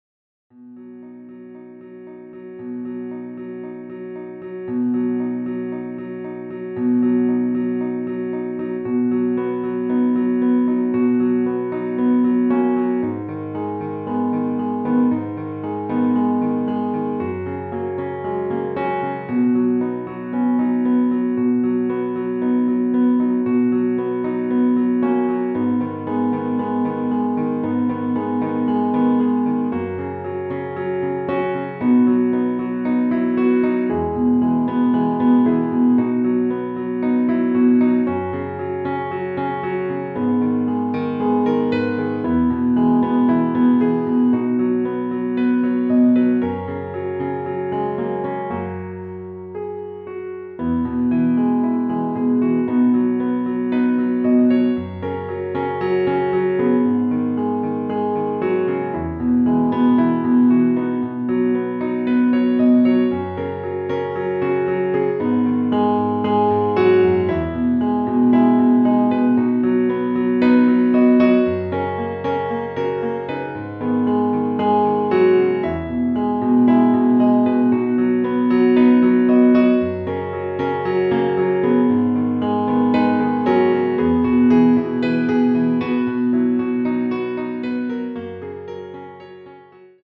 Tonart: H Dur Art: Flügel Version
Wichtig: Das Instrumental beinhaltet NICHT die Leadstimme